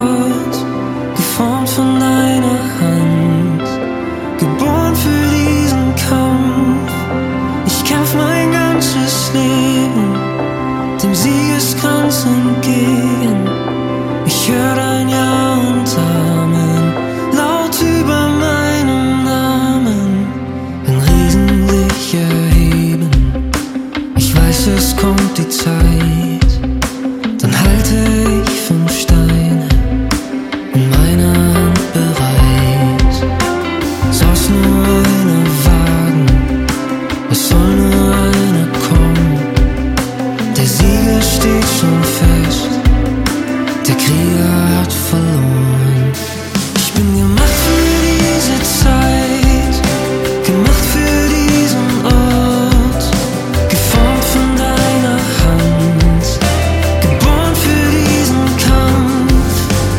Kraftvoll und intensiv.
Die Songs tragen ein neues Gewand im singbaren Stil.
Gesang.